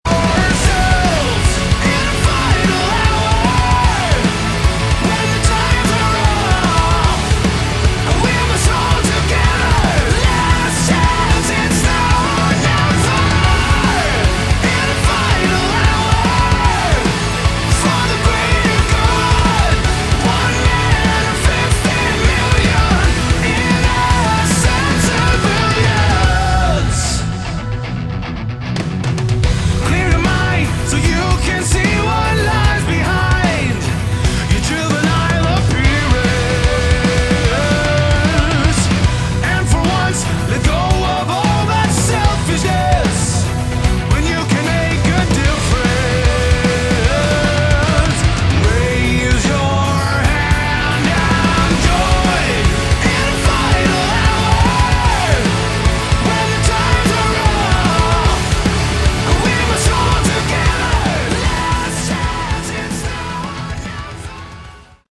Category: Prog Rock/Metal
vocals
guitars, bass
keyboards
drums